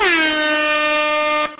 Airhorn Sound